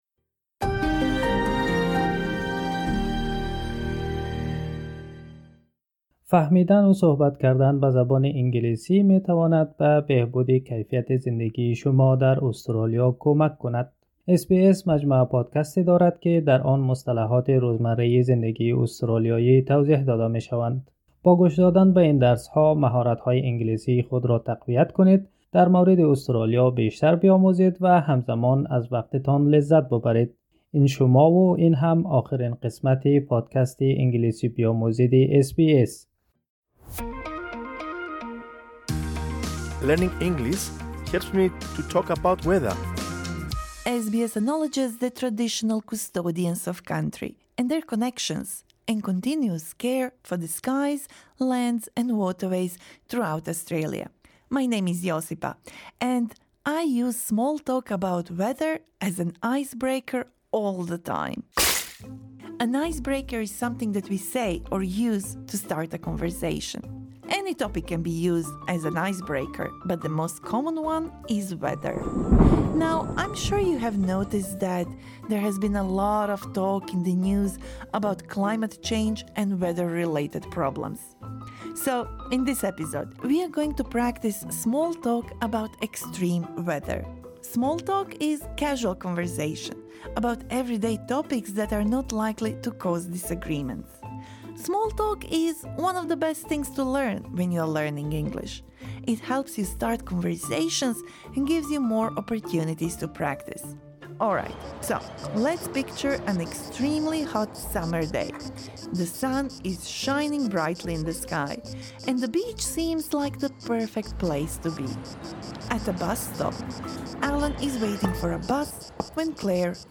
قسمت ۴۴: مکالمه کوتاه در مورد رویدادهای شدید آب‌وهوایی